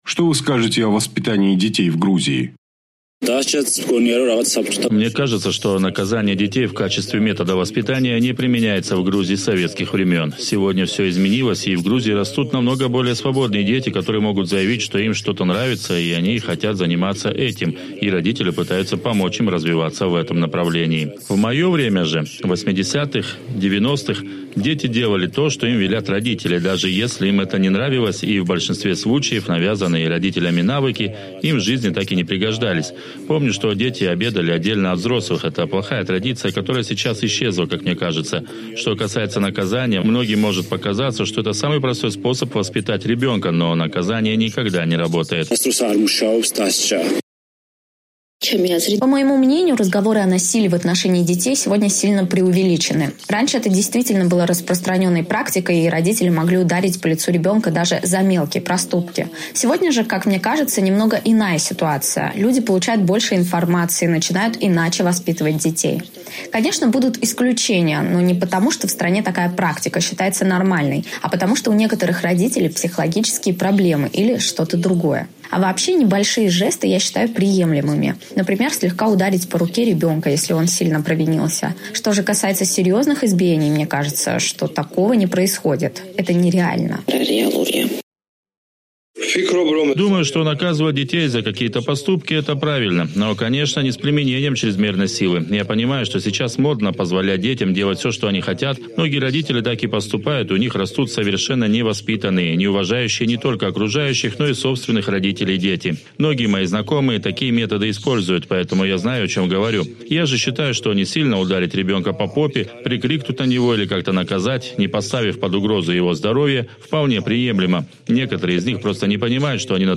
Что подразумевается под хорошим воспитанием ребенка в Грузии? На вопрос «Эха Кавказа» отвечают пользователи соцсетей